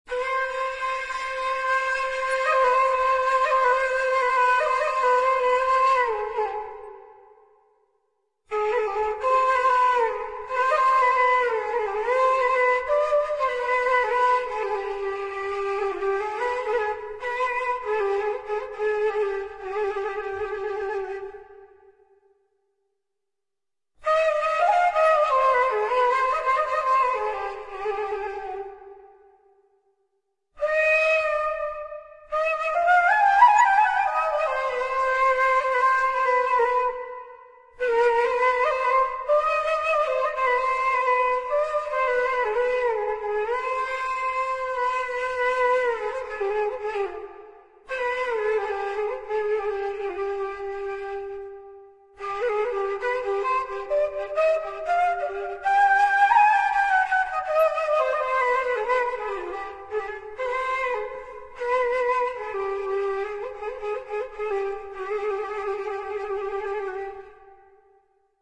اغاني مصرية اغاني لبنانيه اغاني كويتية